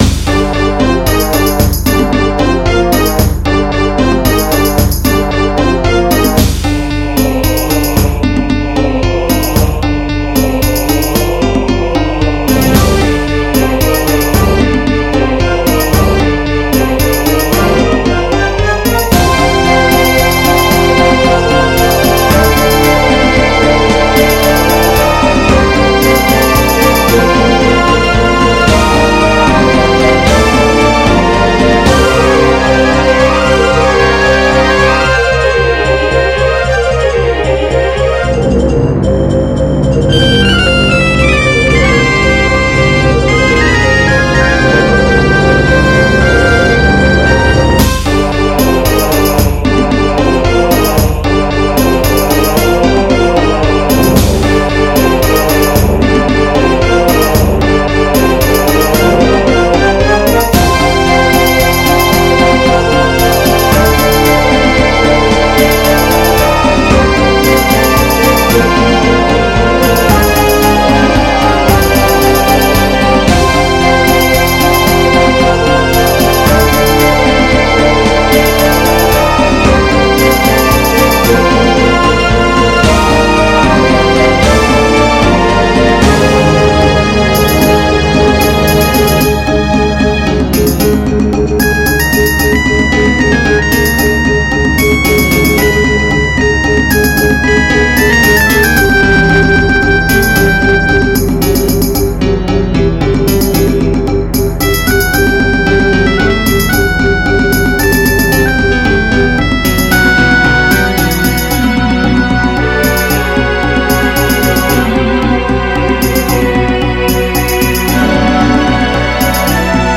custom-made MIDI